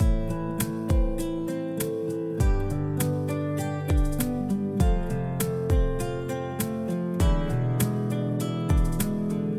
لوپ آرپژ 4/4 شماره 1 گیتار نایلون (پلاس) | هنر صدا
لوپ آرپژ 4/4 شماره 1 گیتار نایلون مجموعه 48 آکورد مینور , ماژور و سون به صورت Left و Right می باشد.